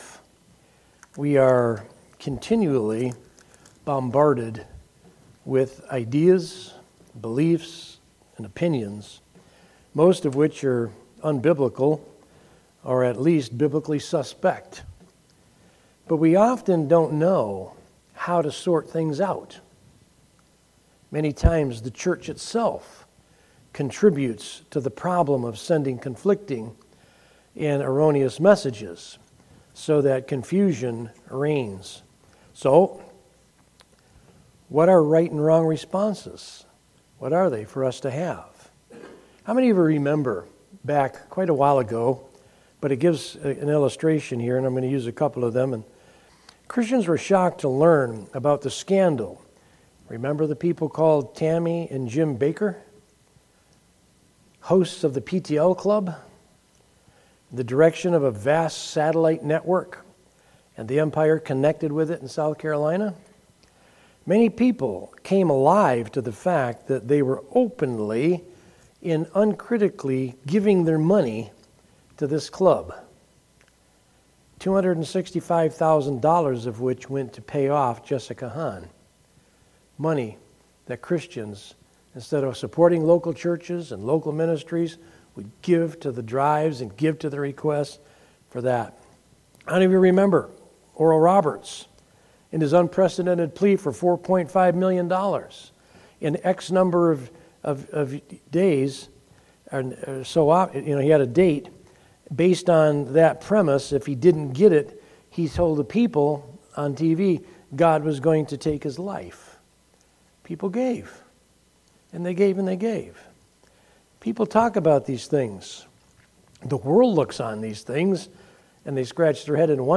From Series: "Sunday Morning - 11:00"
Sermon